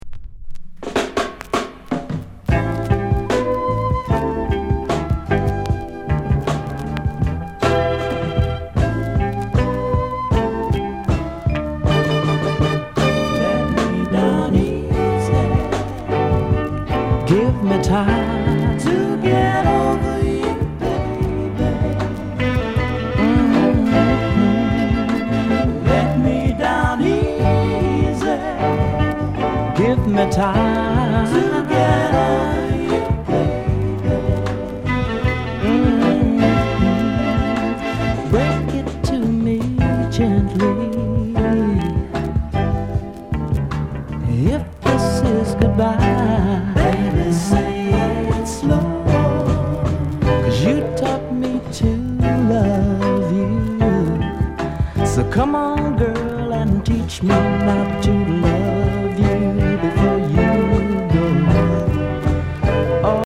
RARE SOUL